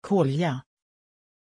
Pronunciation of Kolya
pronunciation-kolya-sv.mp3